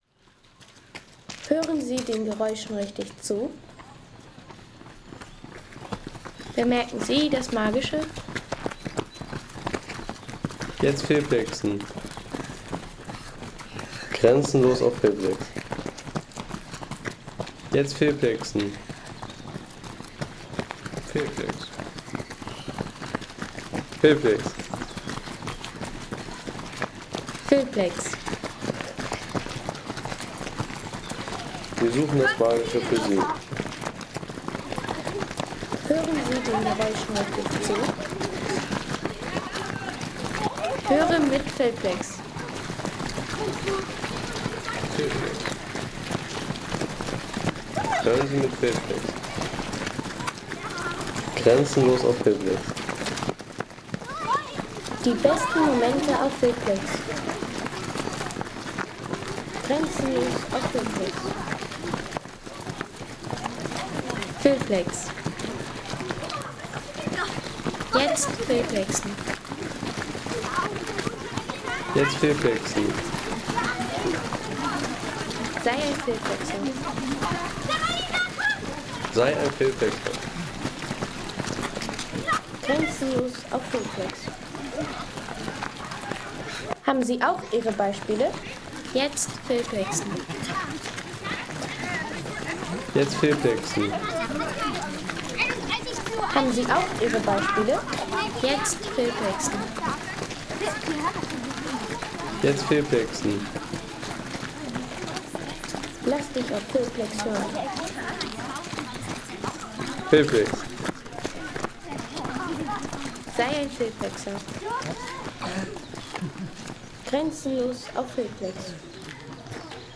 Familien - Marathon
Familien - Marathon Home Sounds Menschen Laufen Familien - Marathon Seien Sie der Erste, der dieses Produkt bewertet Artikelnummer: 170 Kategorien: Menschen - Laufen Familien - Marathon Lade Sound.... Familienfreundlicher Parkmarathon – Stimmen von Erwachsenen und Kind ... 3,50 € Inkl. 19% MwSt.